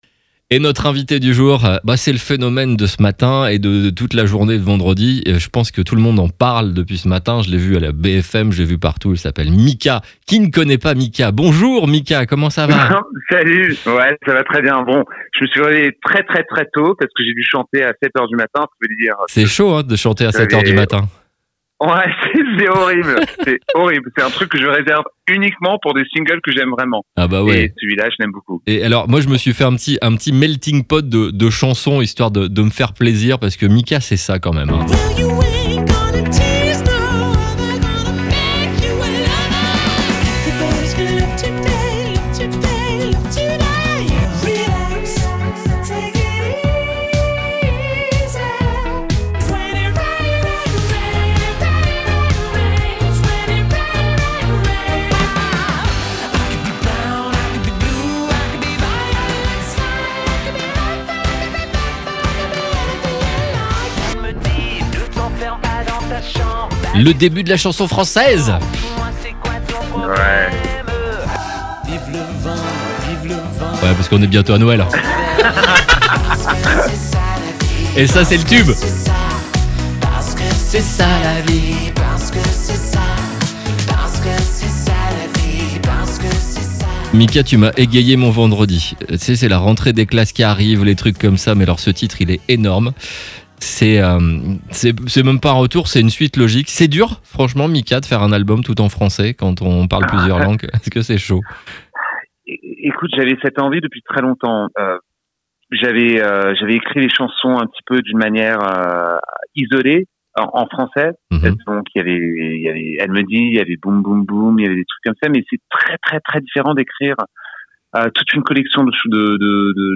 MIKA est de retour avec C'est la vie - Ecoutez son interview ici